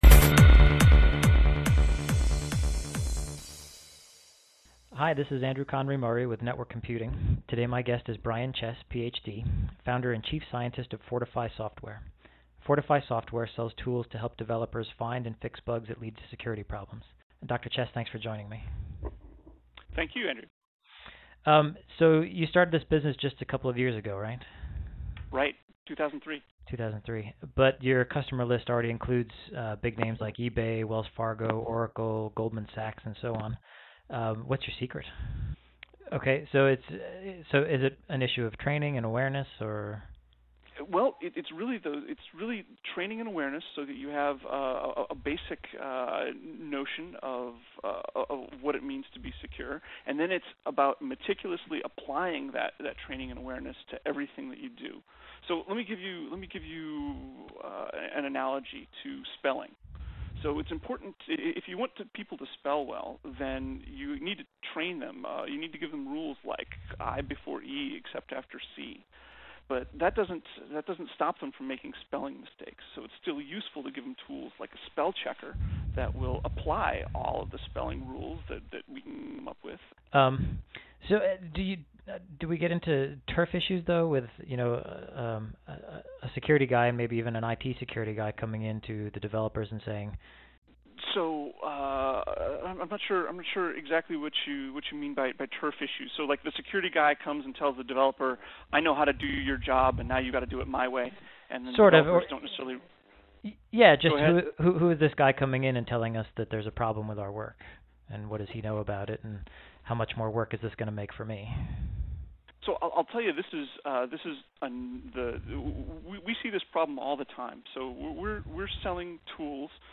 The NWC Interview